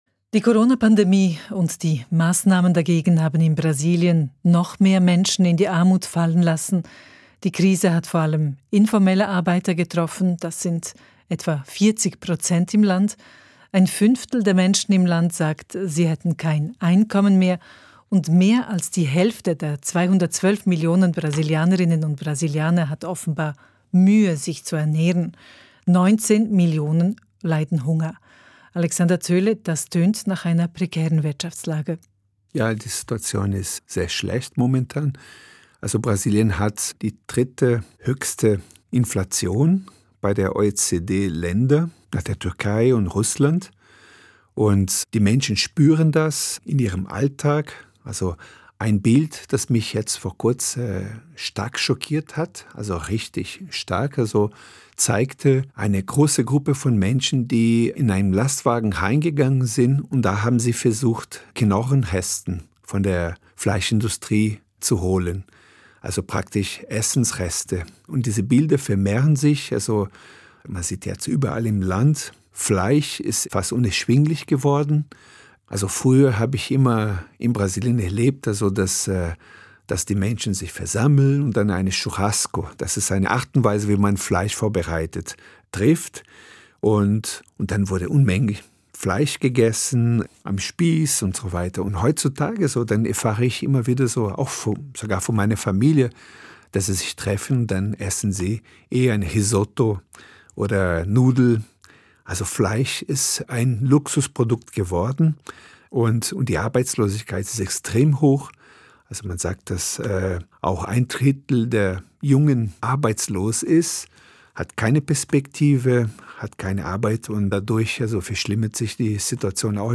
Teil 4 des Gesprächs